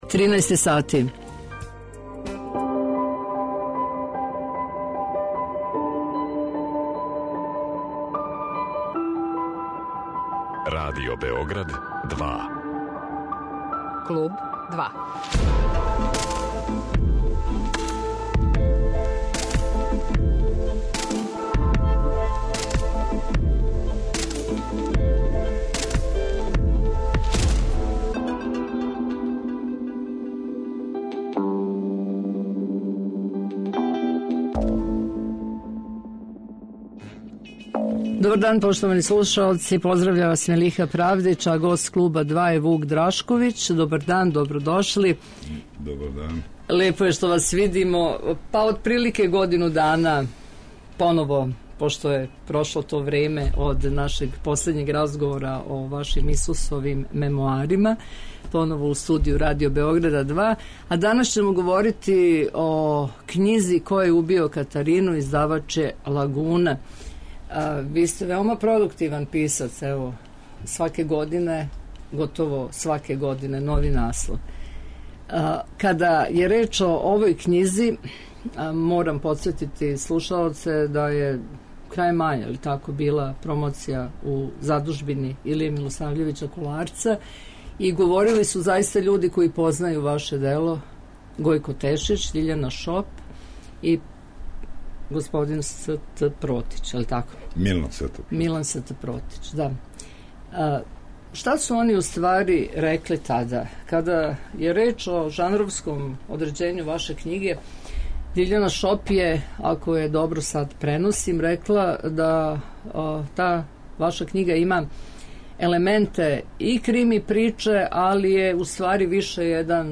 Гост 'Клуба 2' је Вук Драшковић, а говоримо о његовој књизи 'Ко је убио Катарину' (Лагуна).